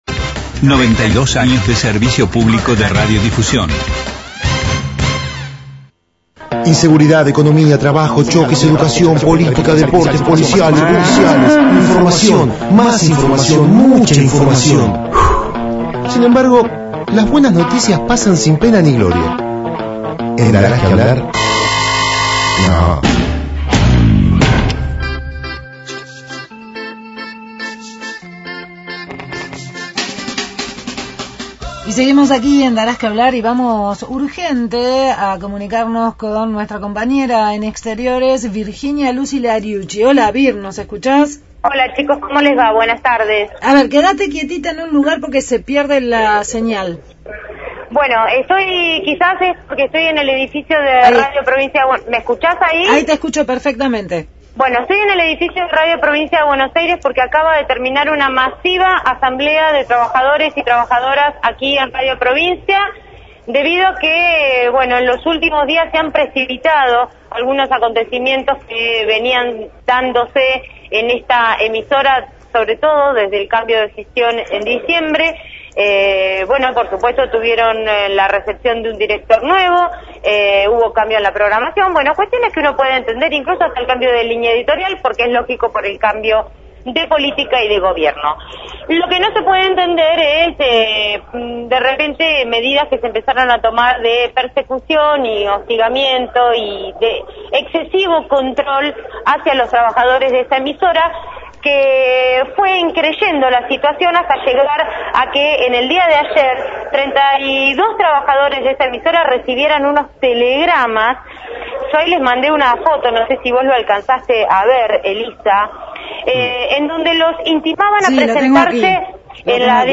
Móvil/ Trabajadores de Radio Provincia en Asamblea por falta de pago – Radio Universidad